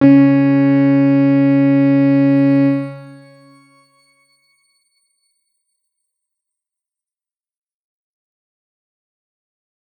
X_Grain-C#3-pp.wav